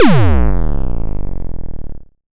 ChipTune Arcade FX 01.wav